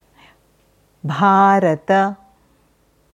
Dann sieht das so aus: भारत, in der IAST Umschrift bhārata. Hier hörst du, wie man Bharata ausspricht.